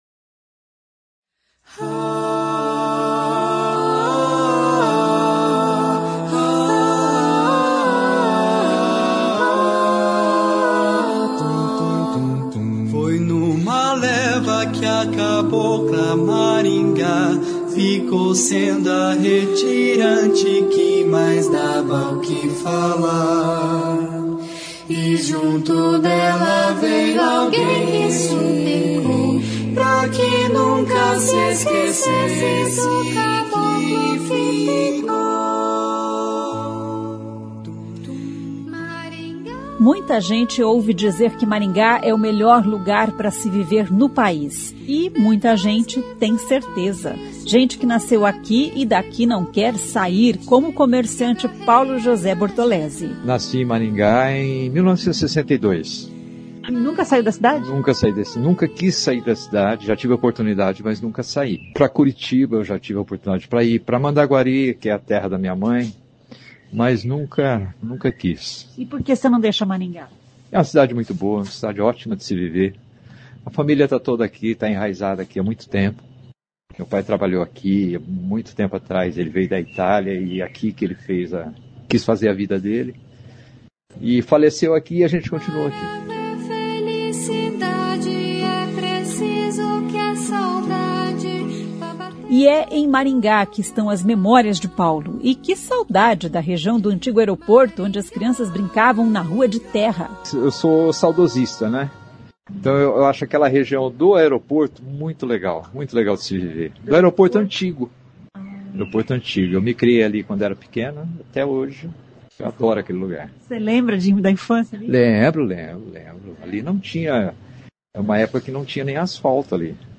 A reportagem foi sonorizada com a música Maringá, de Joubert de Carvalho, interpretada pelo grupo Alla Capella, formado por egressos do curso de música da UEM.